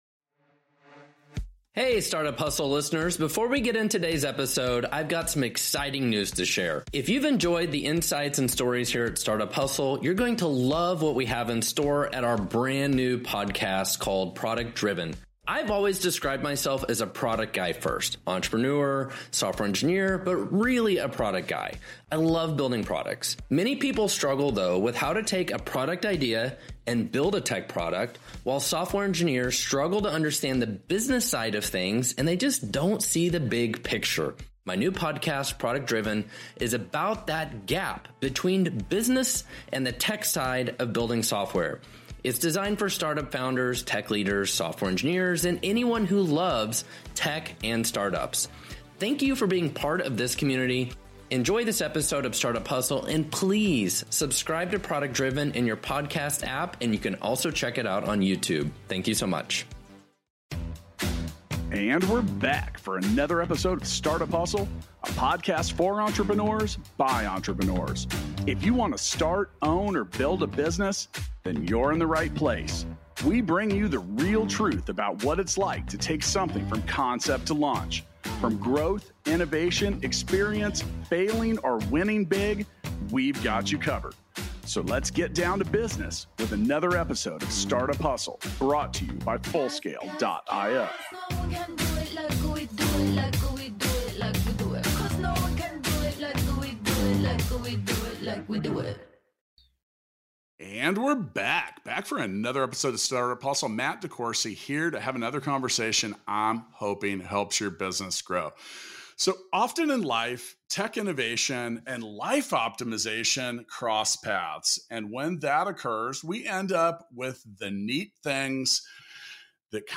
Hear a great conversation about the difference between doing business in fashion and in hardware. They also talk about consumer awareness, building a moat, dealing with IP imitations, and the importance of vendor agreements and relationships.